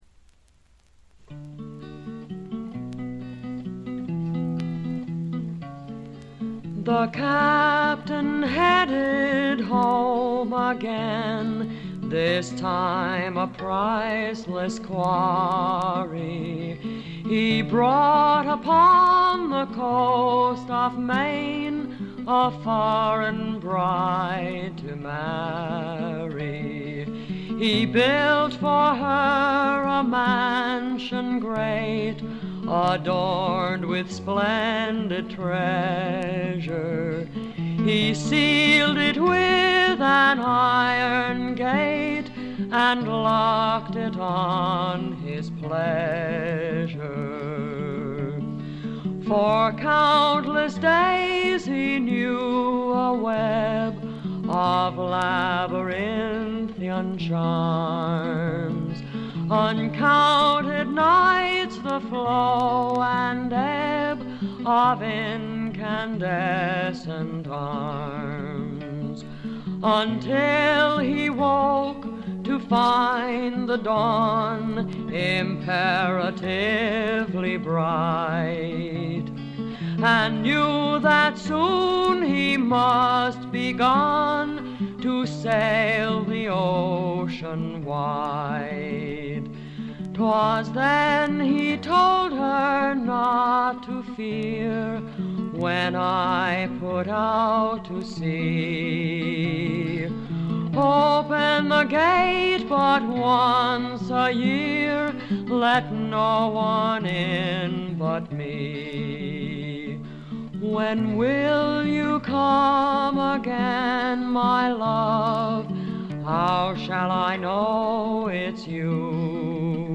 軽微なバックグラウンドノイズにチリプチ少し。
魅力的なヴォイスでしっとりと情感豊かに歌います。
試聴曲は現品からの取り込み音源です。
Recorded At - WDUQ, Pittsburgh, PA